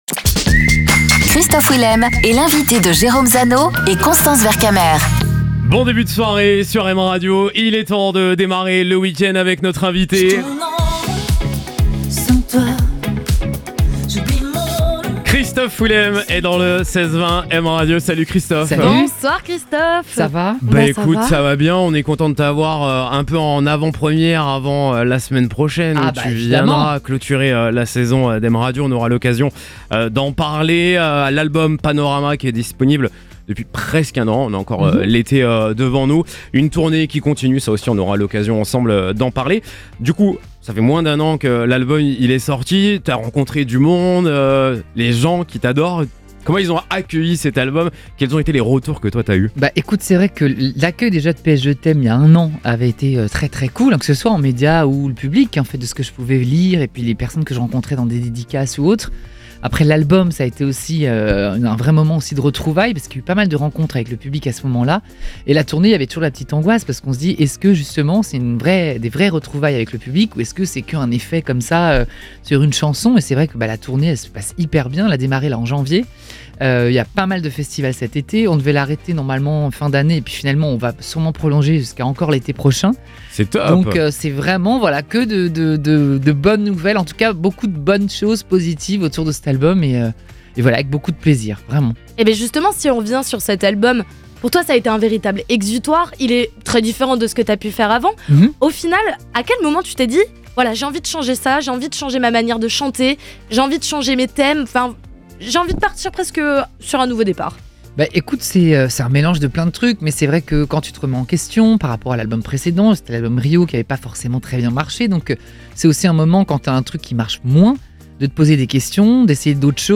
Christophe Willem était l'invité du 16/20 M Radio